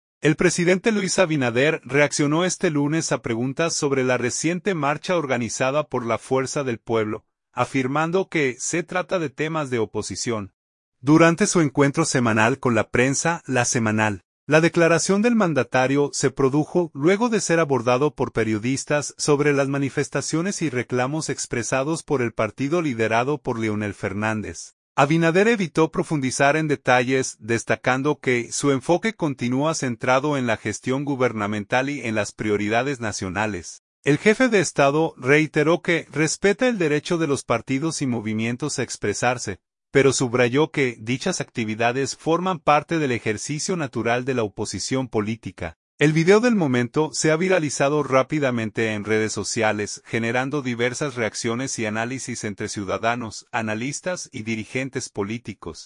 El presidente Luis Abinader reaccionó este lunes a preguntas sobre la reciente marcha organizada por la Fuerza del Pueblo, afirmando que se trata de “temas de oposición”, durante su encuentro semanal con la prensa, LA Semanal.